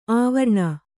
♪ āvarṇa